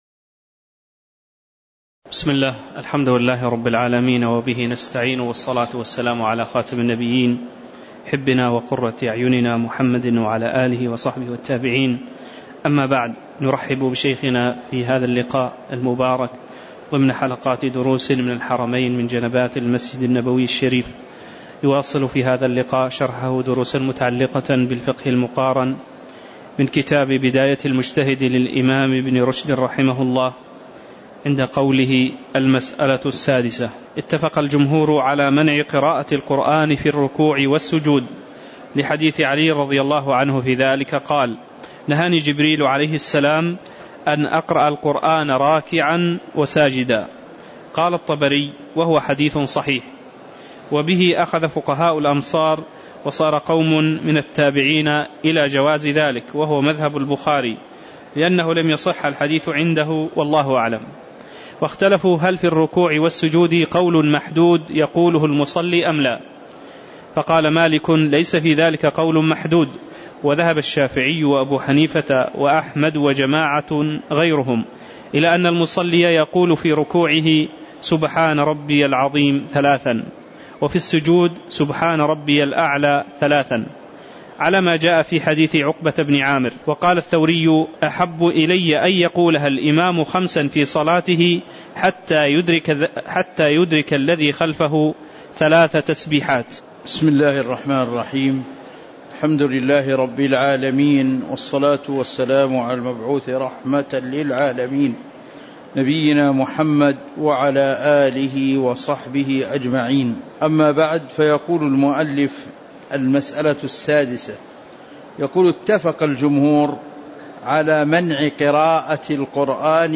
تاريخ النشر ١١ ربيع الثاني ١٤٤١ هـ المكان: المسجد النبوي الشيخ